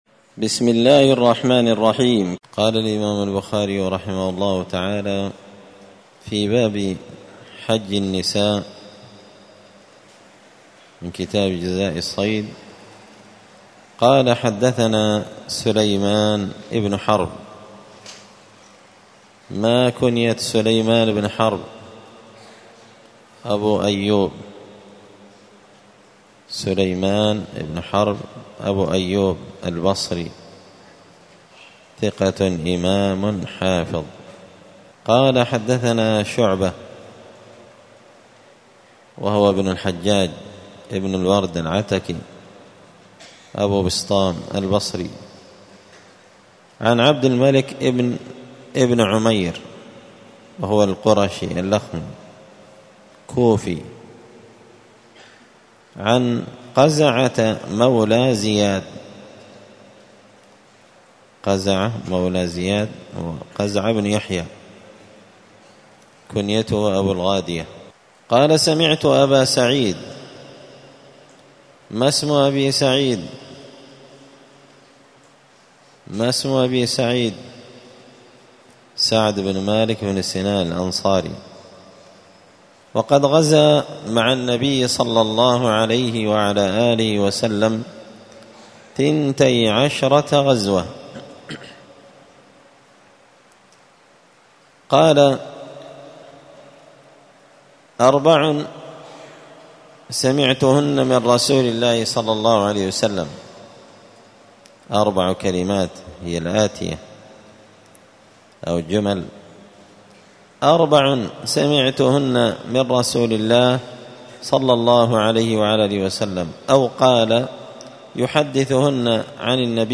مسجد الفرقان قشن المهرة اليمن 📌الدروس اليومية